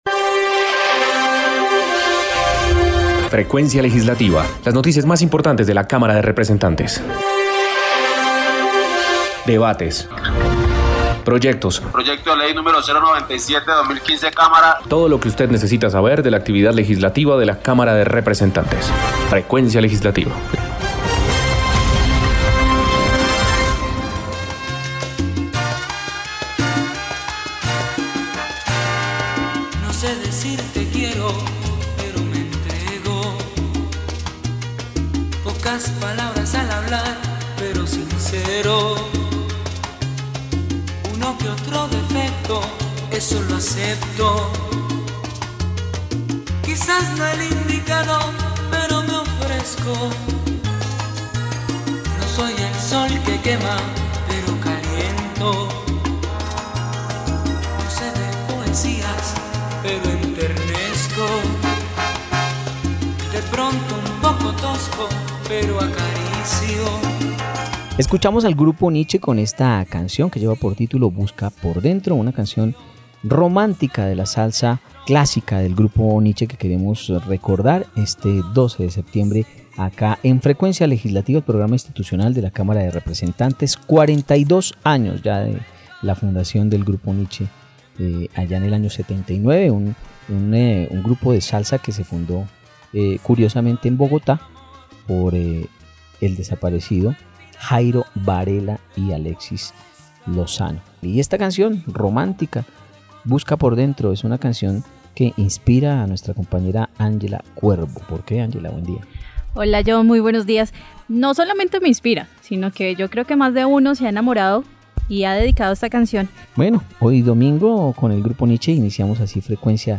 Programa Radial Frecuencia Legislativa. Domingo 12 de Septiembre de 2021